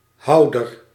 Ääntäminen
IPA: [ʁe.si.pjɑ̃]